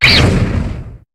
Cri de Pyronille dans Pokémon HOME.